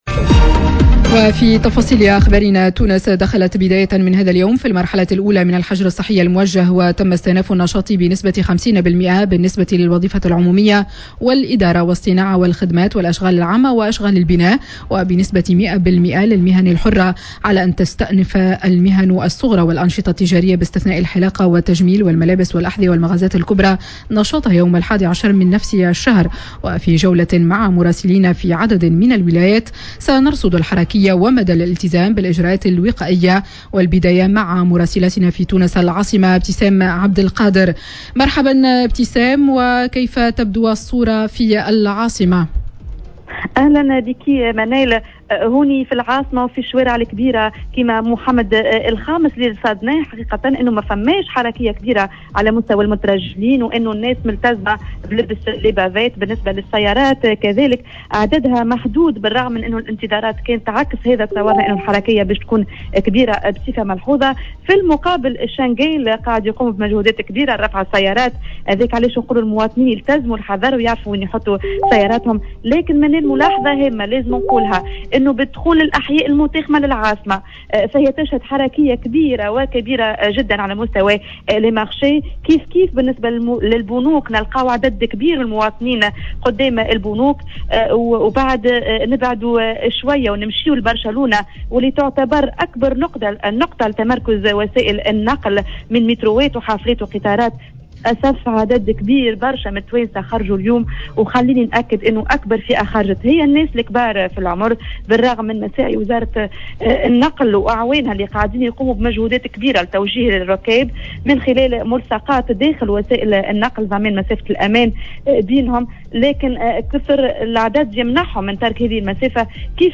جولة مع عدد من المراسلين